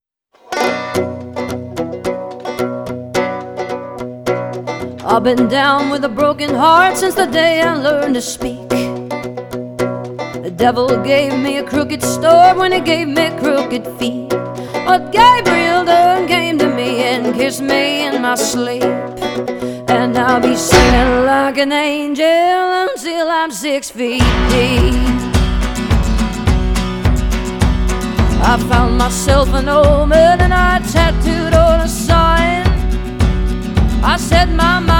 Жанр: Рок / Альтернатива / Кантри